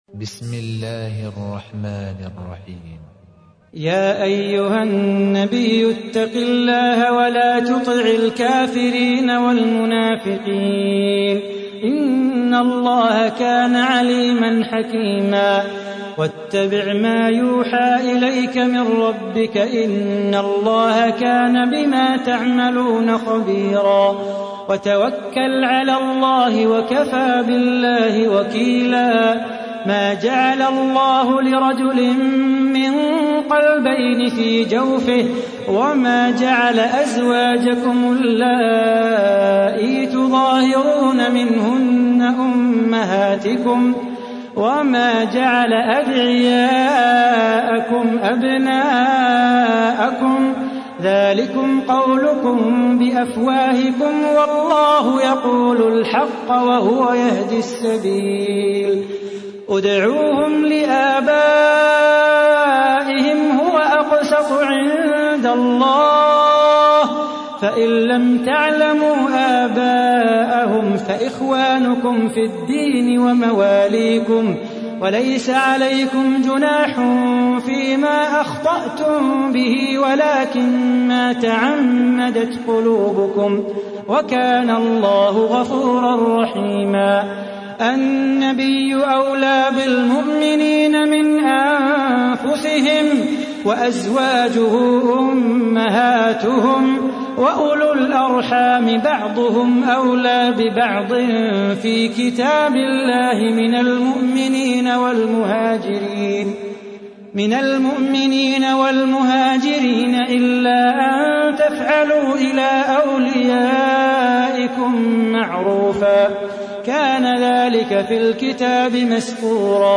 تحميل : 33. سورة الأحزاب / القارئ صلاح بو خاطر / القرآن الكريم / موقع يا حسين